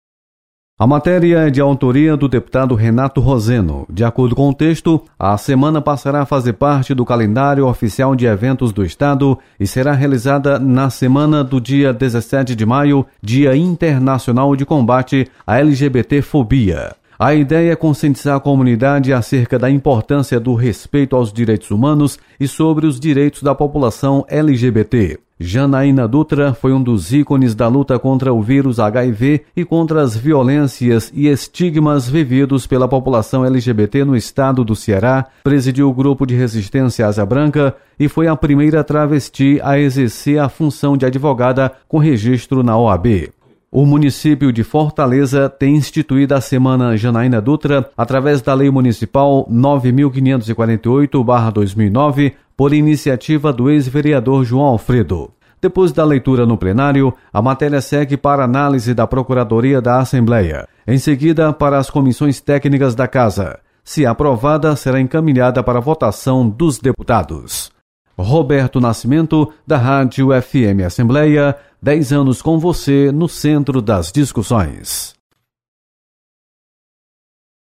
Projeto cria Semana Janaína Dutra em respeito à diversidade sexual. Repórter